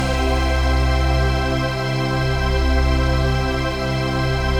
CHRDPAD084-LR.wav